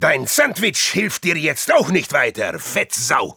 Voice file from Team Fortress 2 German version.
Spy_dominationheavy06_de.wav